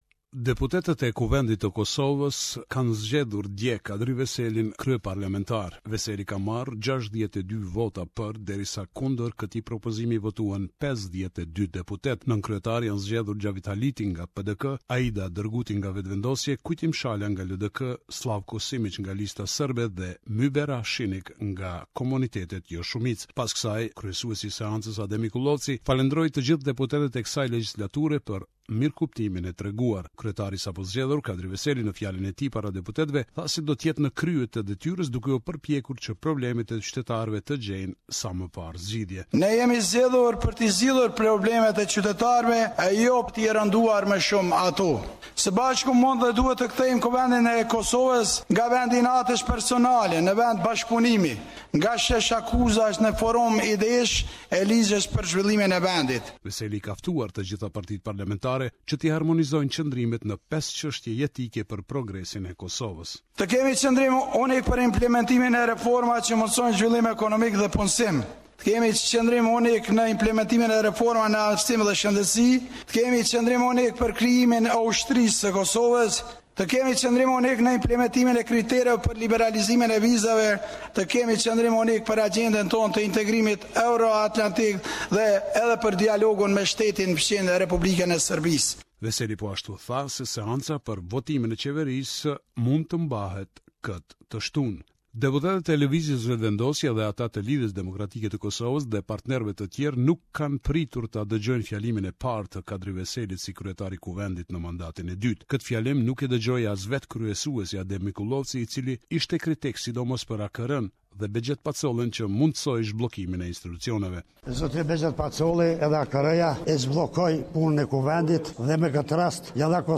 Raporti nga Prishtina.